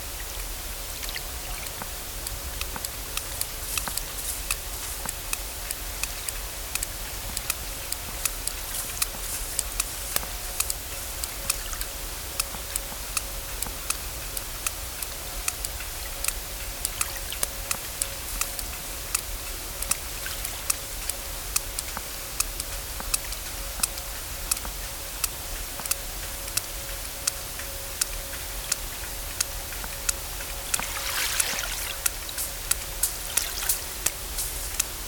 Dazu sendet er Klicks aus, die eine Lautstärke von über 200 Dezibel erreichen können und diese Klicks können wir auch an Bord unseres Forschungsschiffs nachverfolgen.
Wir nutzen ein Unterwassermikrophon, ein sogenanntes Hydrophon, das die Geräusche unter der Meeresoberfläche in einem Radius von rund 10 Kilometern aufzeichnet. Über Kopfhörer können wir so der Unterwasserwelt lauschen und sind durch die charakteristischen Klick-Laute des Pottwals informiert, wenn er in der Nähe sein sollte.
Pottwal-Klicks, Juni 2021